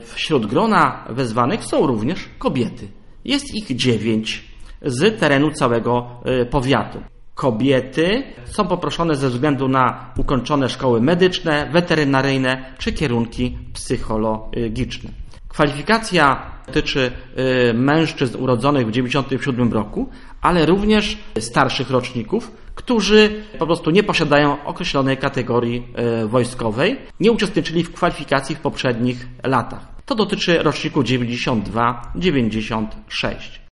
Przed komisjami staną również kobiety posiadające kwalifikacje przydatne do czynnej służby wojskowej oraz mężczyźni spoza rocznika podstawowego, ponownie Andrzej Hłond.